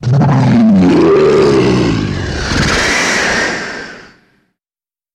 На этой странице собраны разнообразные звуки Змея Горыныча: от грозного рыка до зловещего шипения.